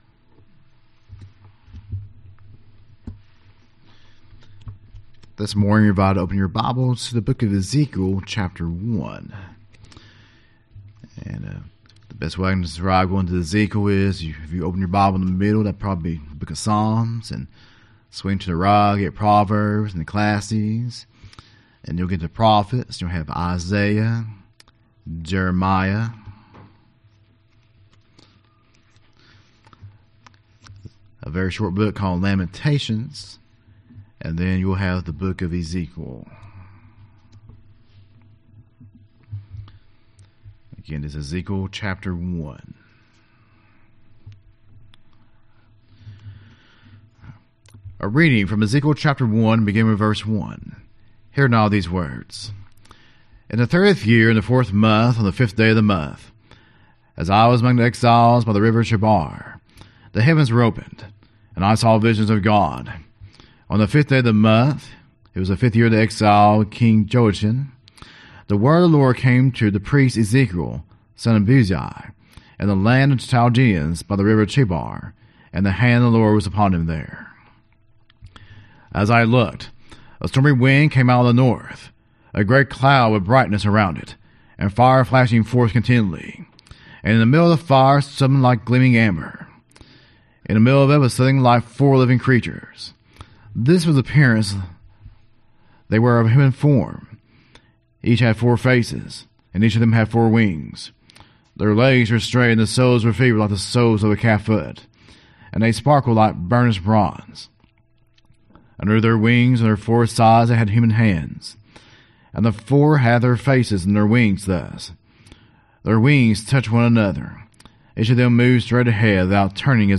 Sermon Audio Downloads | Mars Hill United Methodist Church